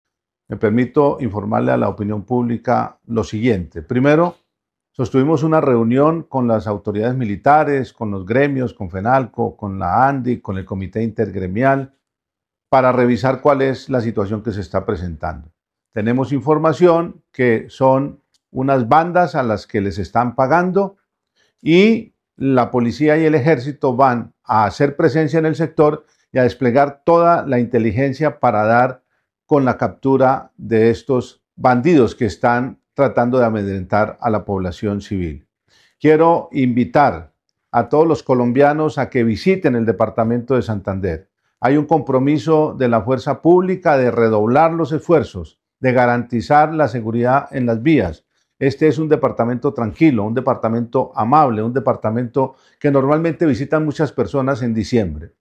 Gobernador de Santander, Juvenal Díaz Mateus